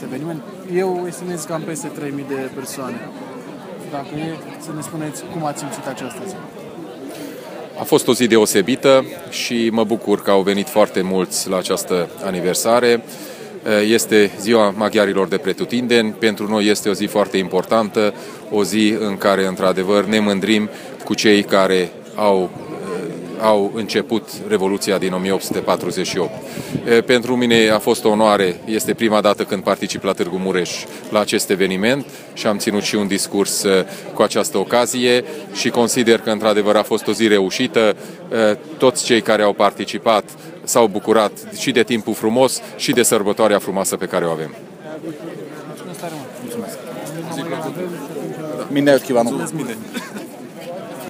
Primele activități s-au încheiat la Monumentul Secuilor Martiri din localitate, unde a avut loc un concert de muzică populară și o ceremonie de depuneri de coroane.
Au luat cuvântul Soltész Miklós, secretar de stat responsabil pentru culte, națiuni și relația cu societatea civilă în Guvernul Ungar și Péter Ferenc, președintele Consiliului Județean Mureș și președinte al Organizației județene UDMR Mureș.